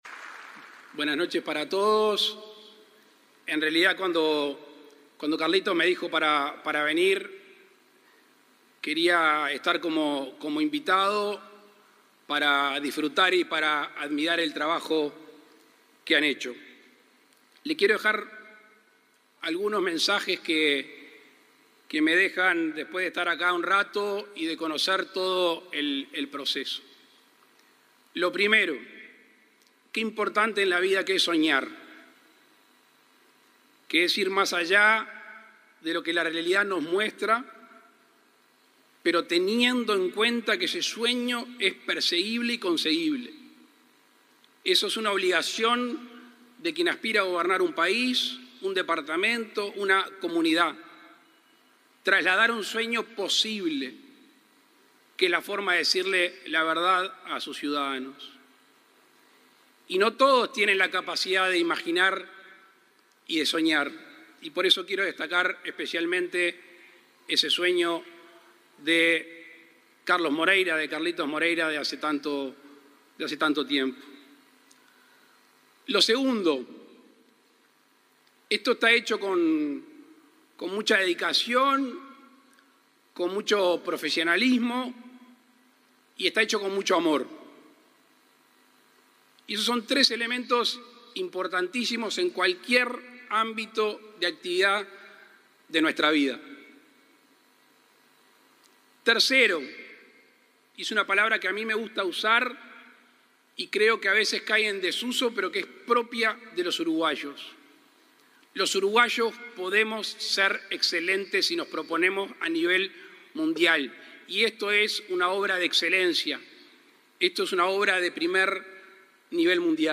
Palabras del presidente Luis Lacalle Pou
El presidente Luis Lacalle Pou participó ayer en Colonia en el acto de reinauguración de la Plaza de Toros Real de San Carlos y el lanzamiento de la